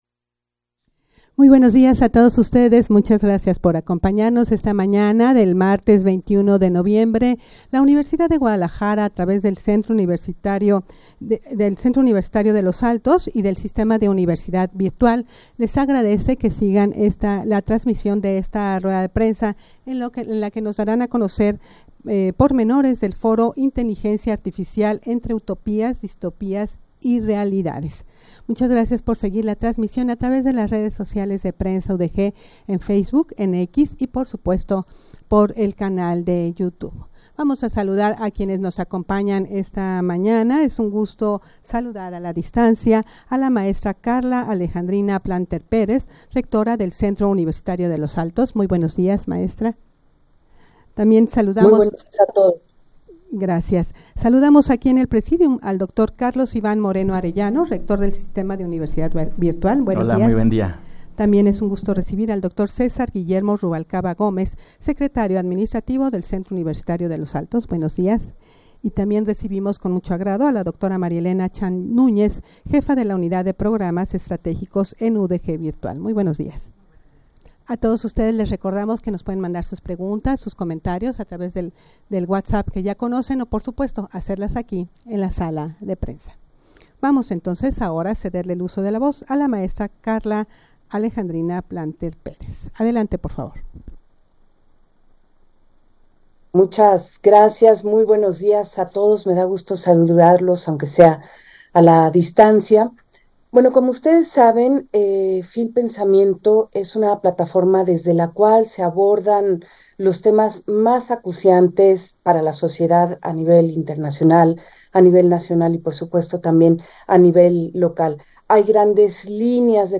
Audio de la Rueda de Prensa
rueda-de-prensa-foro-inteligencia-artificial-entre-utopias-distopias-y-realidades.mp3